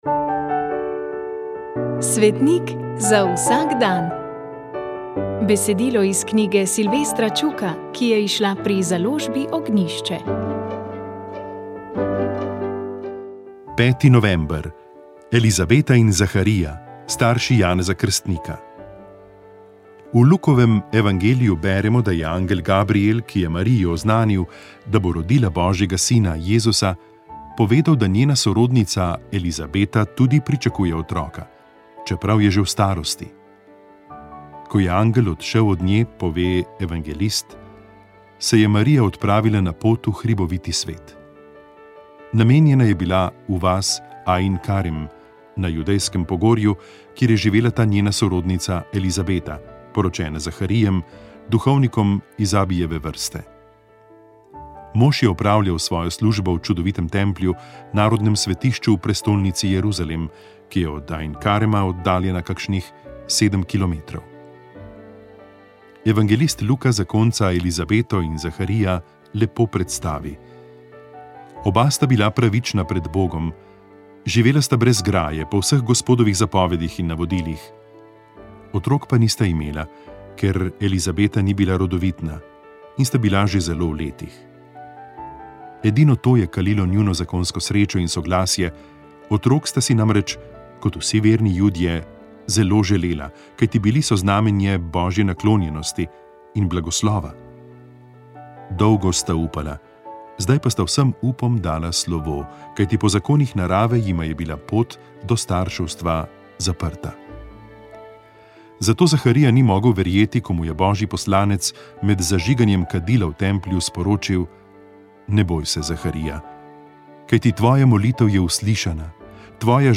svetovanjebranjepogovorizobraževanje